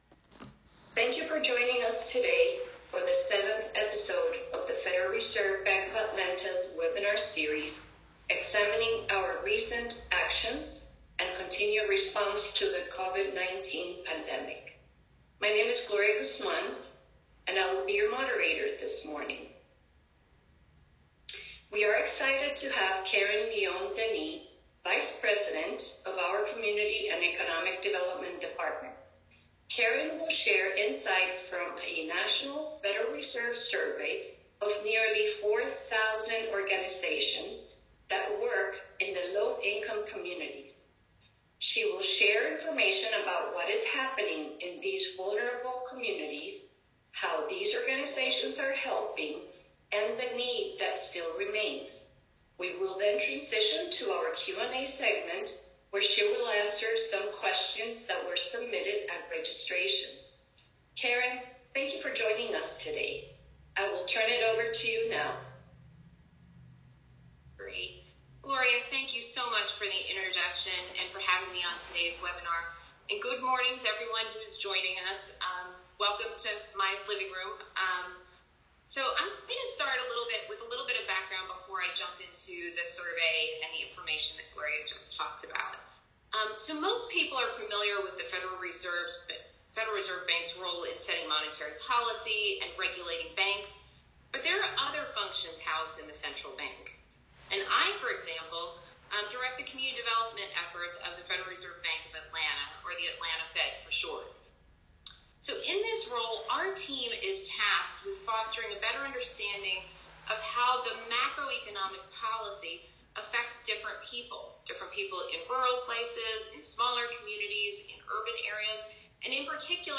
Watch to learn more about what is happening in these vulnerable communities, how these organizations are helping, and the needs that still remain. Presentation Transcript